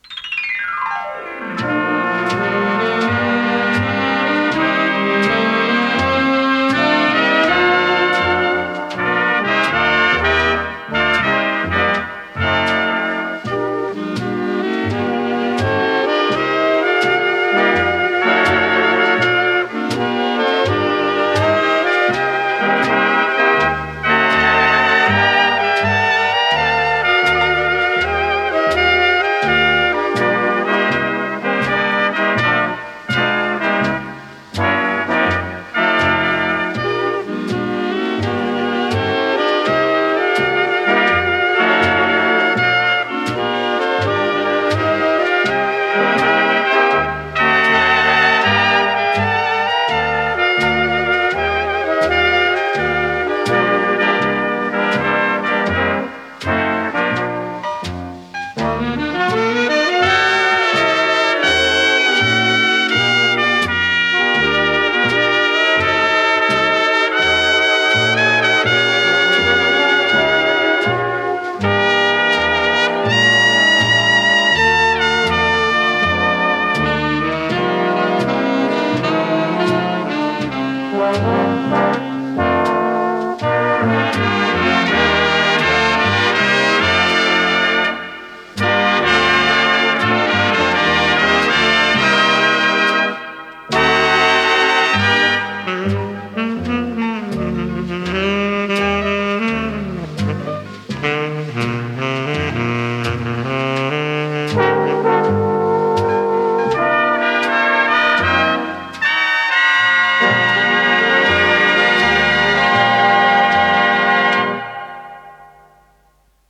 труба
ВариантДубль моно